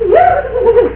The files have to be mono, .wav, u-law at 8khz and less than 100K.
Three Stooges Ringtones:
Curly “Woob Woob Woob
woob_woob.wav